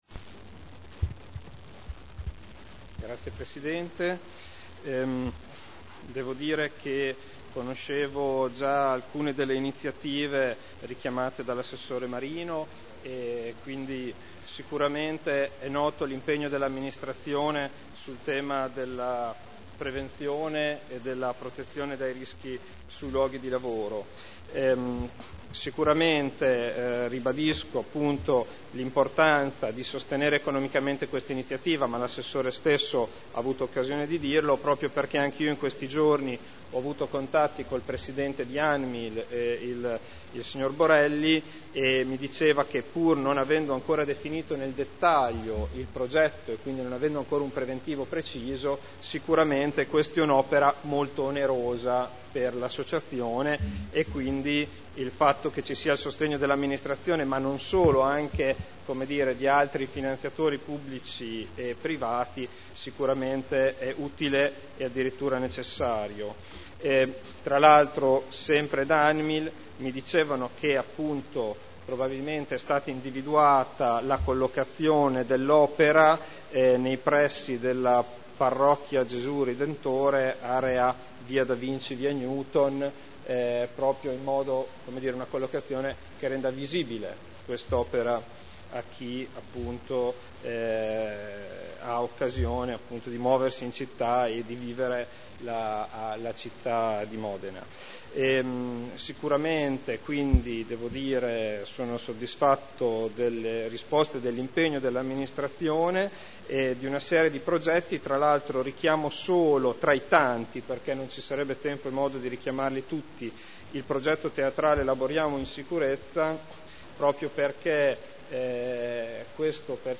Seduta del 07/02/2011. Interrogazione del consigliere Ricci (Sinistra per Modena) avente per oggetto: "Monumento ANMIL"